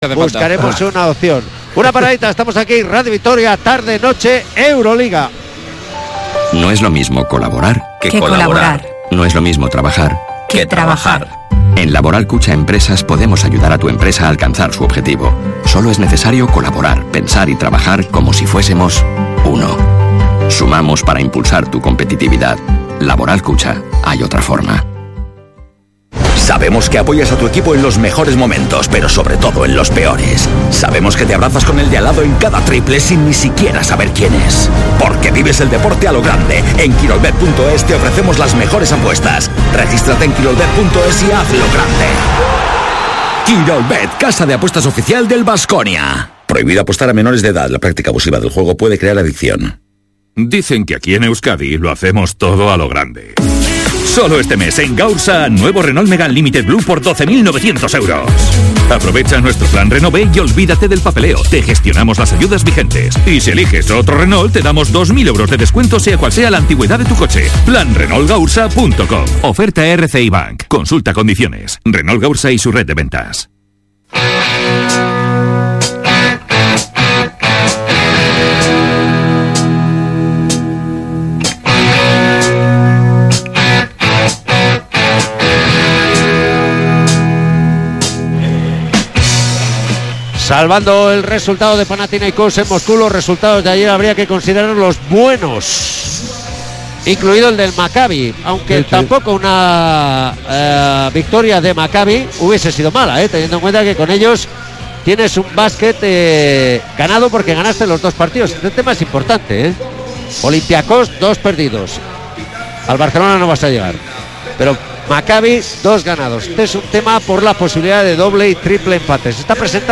Kirolbet Baskonia-Buducnost jornada 26 euroleague 2018-19 retransmisión Radio Vitoria